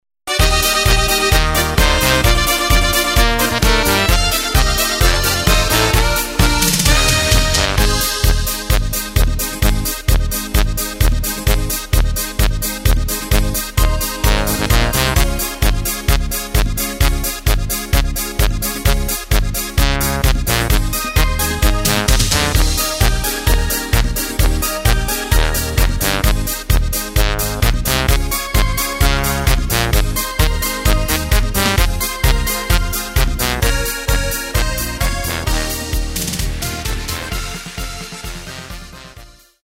Takt: 2/4 Tempo: 130.00 Tonart: Bb
Schlager-Polka